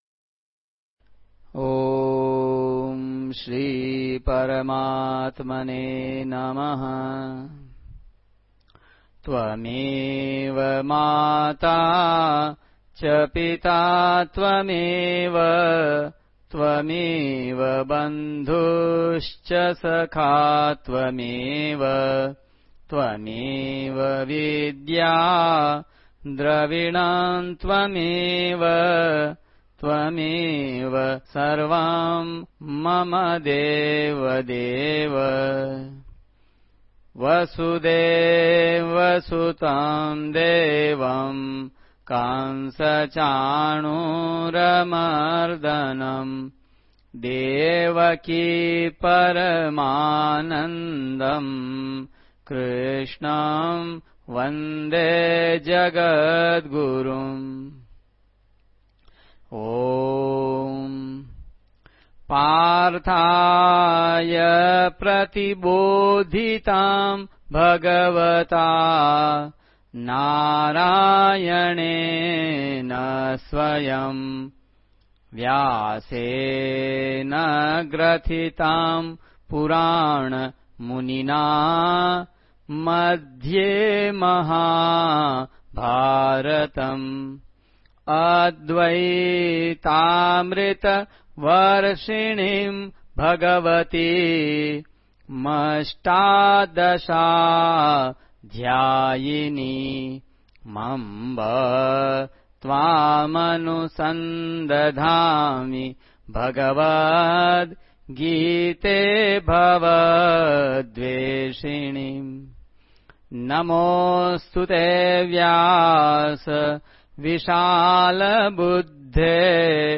मूळ गीता पाठ